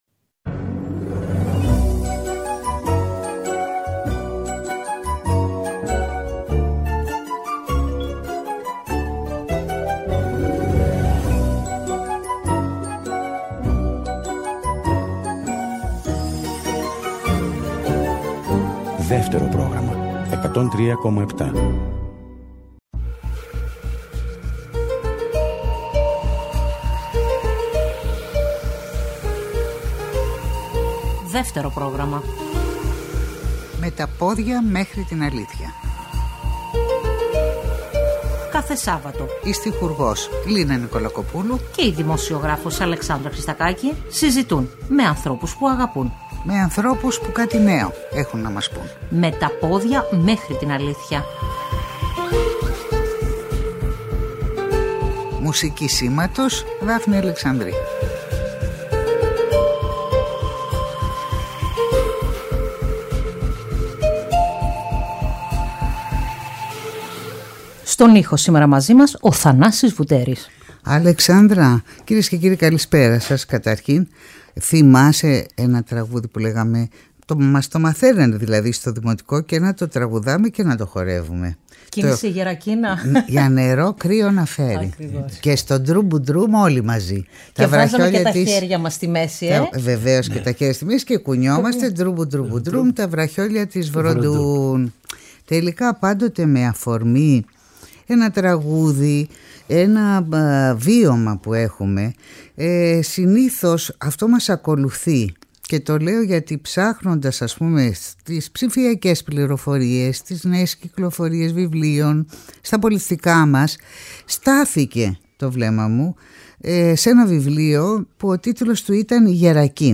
φιλοξενούν στο στούντιο
Απαγγέλει από μνήμης 3 δικά του ποιήματα